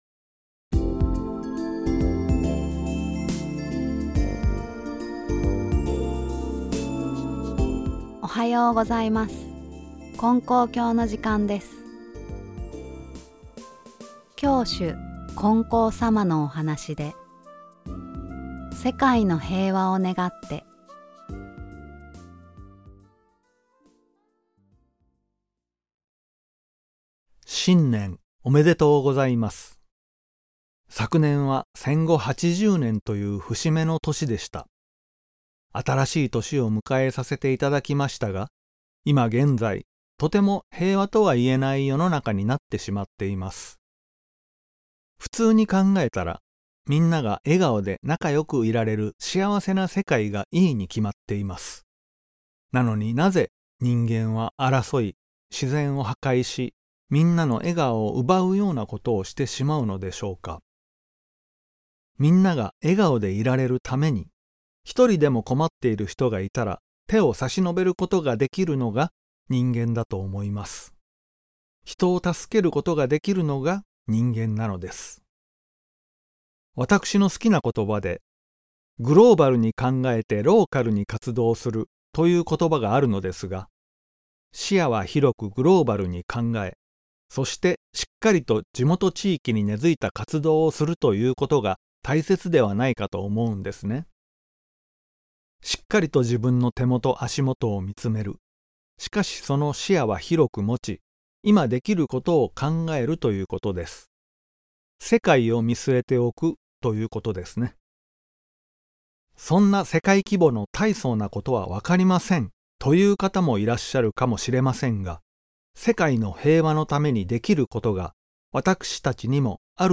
●年頭放送